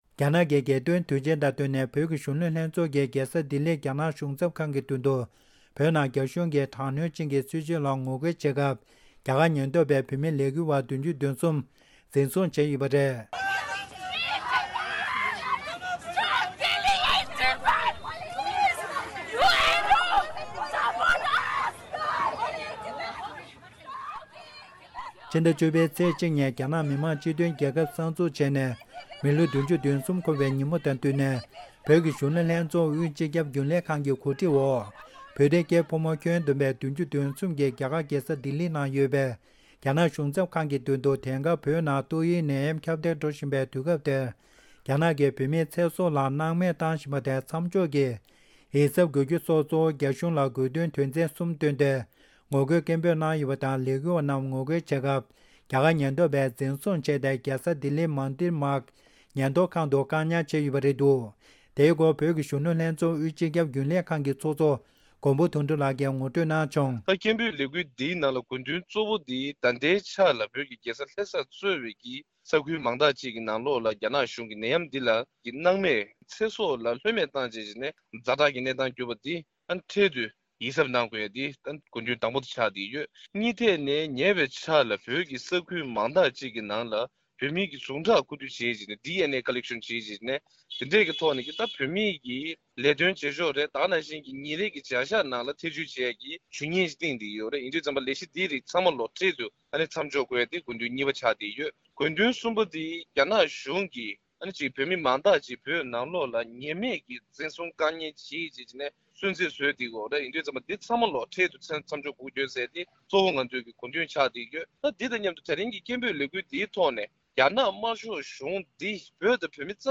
བོད་ཀྱི་གཞོན་ནུ་ལྷན་ཚོགས་ཀྱིས་རྒྱལ་ས་ལྡི་ལིར་རྒྱ་ནག་གཞུང་ཚབ་ཁང་མདུན་དུ་ངོ་རྒོལ་སྐད་འབོད་གནང་སྐབས་ལས་འགུལ་བ་༧༣་འཛིན་བཟུང་བྱེད་ཡོད་པ། སྒྲ་ལྡན་གསར་འགྱུར།